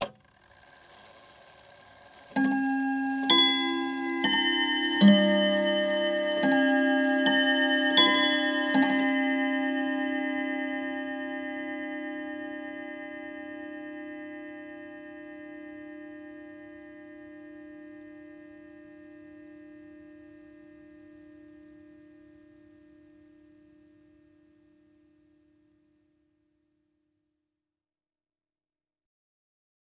Clock Half Hour Chime.wav